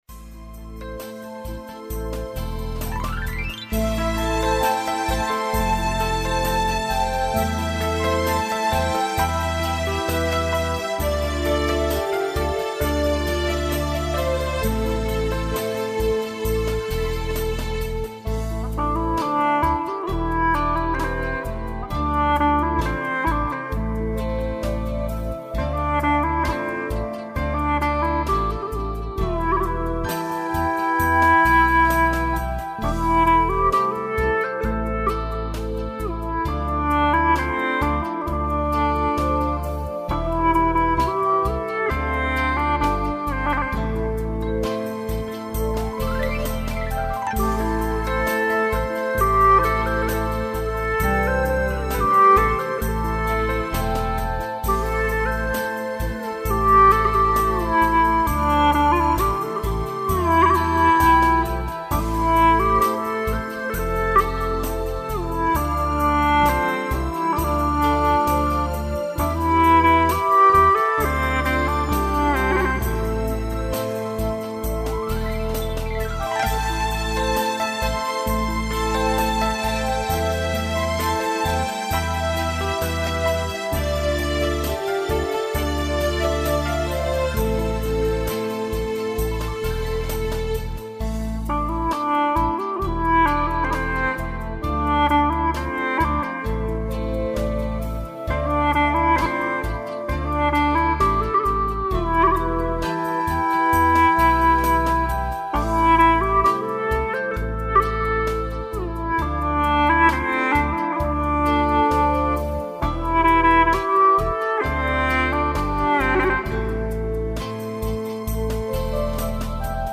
调式 : F1